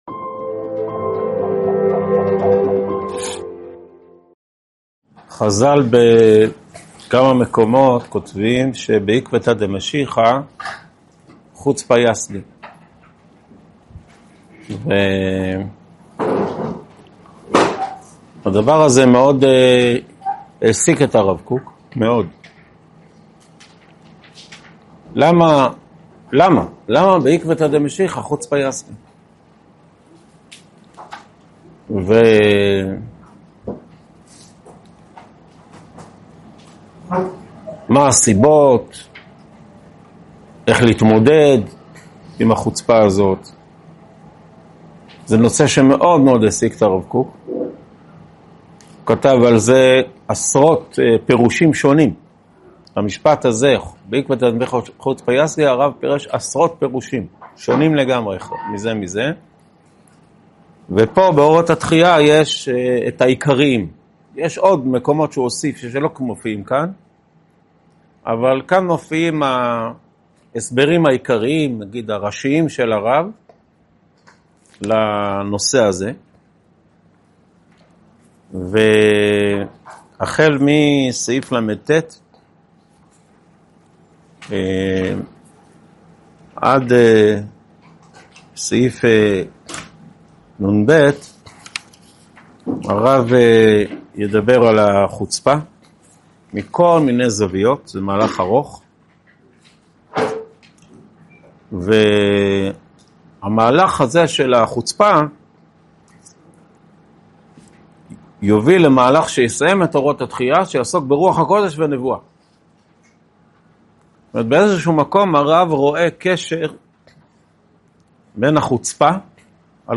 שיעור 42 מתוך 61 בסדרת אורות התחיה
הועבר בישיבת אלון מורה בשנת תשפ"ה.